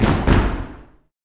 YOUTHFEET1-L.wav